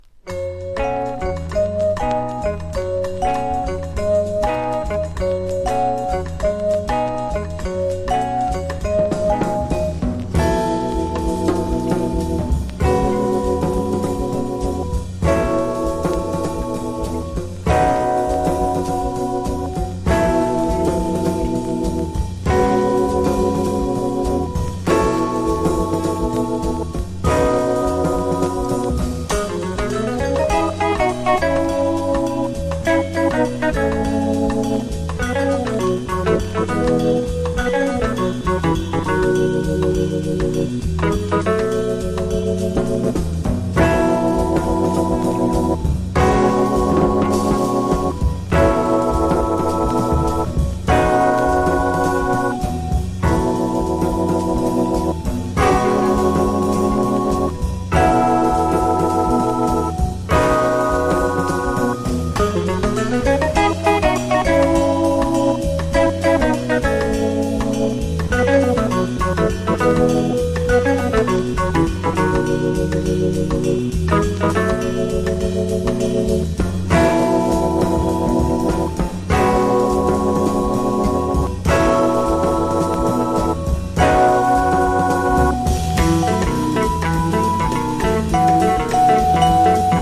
ヴァイブとオルガンが絶妙に調和した、他とは一味違うオルガン・ソウル・ジャズが味わえる名盤！